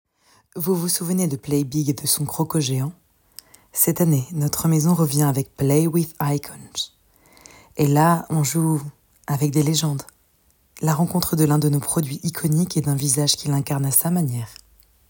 Lacoste voix off